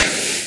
PixelPerfectionCE/assets/minecraft/sounds/mob/creeper/say3.ogg at mc116